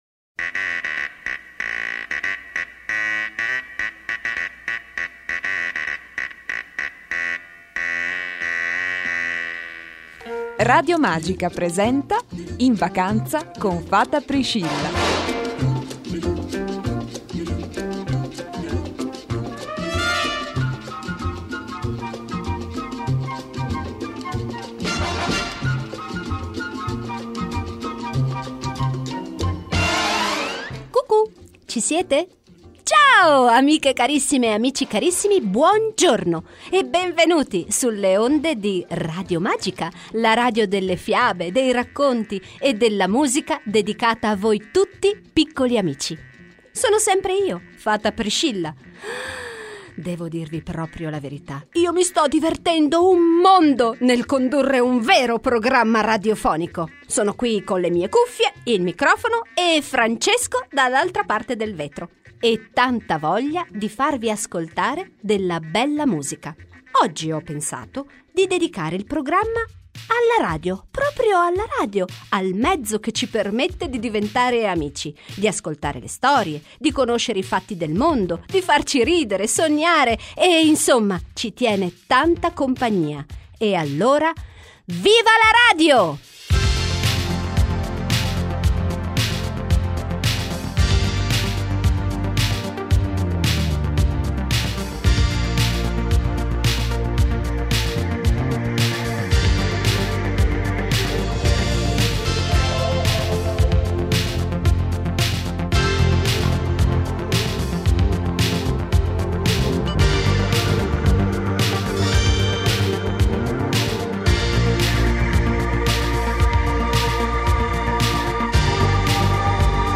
Cinque puntate con lo scoiattolo Silvano, le storie fata Priscilla e un po’ di musica.